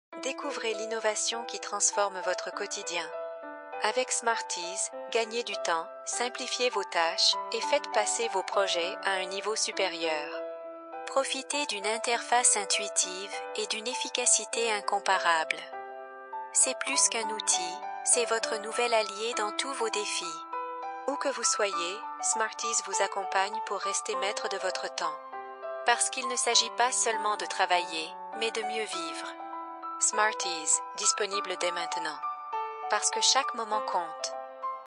Approachable
Articulate
Believable